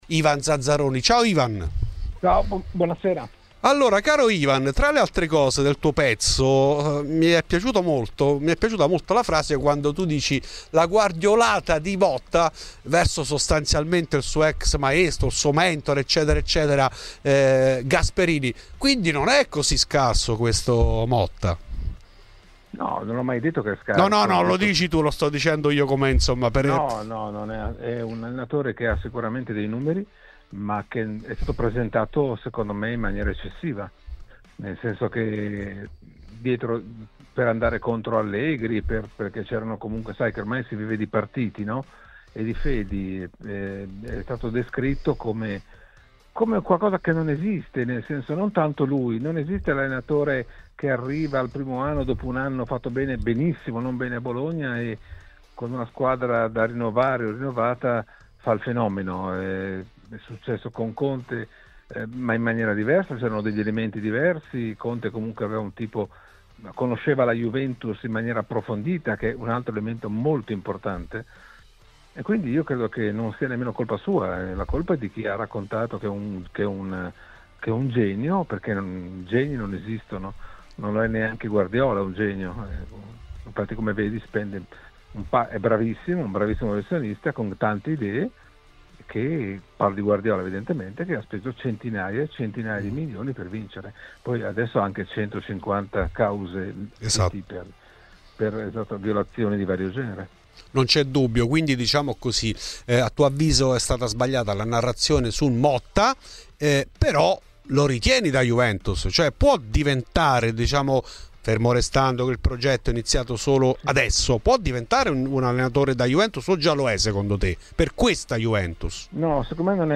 In ESCLUSIVA a Fuori di Juve Ivan Zazzaroni, direttore del Corriere dello Sport.
Nel podcast l'intervista integrale